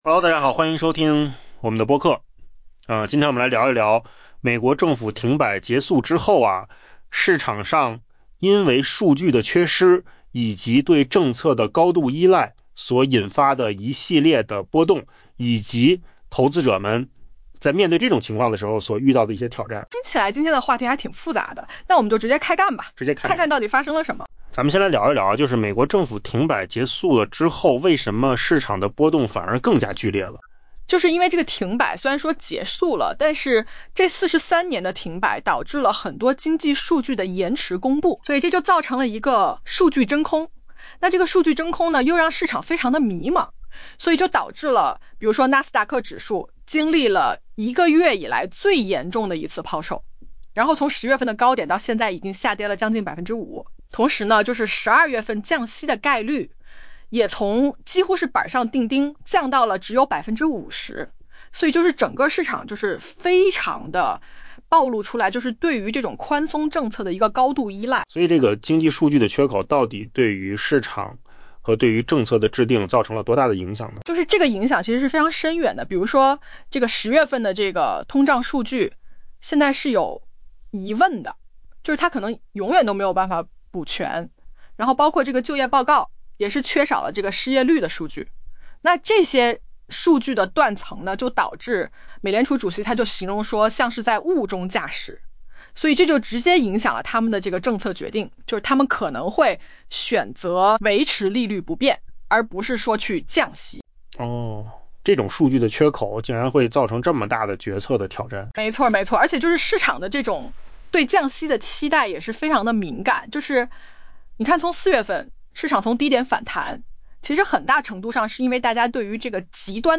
AI 播客：换个方式听新闻 下载 mp3 音频由扣子空间生成 美国政府停摆已经结束，但对投资者而言，后遗症才刚刚开始。